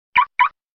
Звук включения сигнализации на машине - на СМС
• Категория: Рингтон на смс